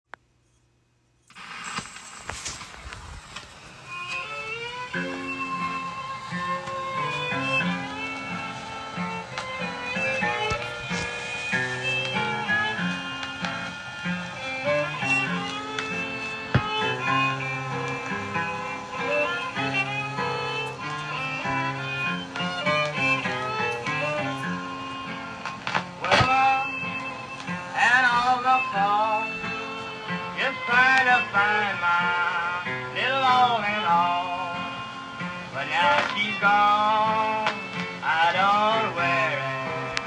Both sides “without coin” plays and skips the same.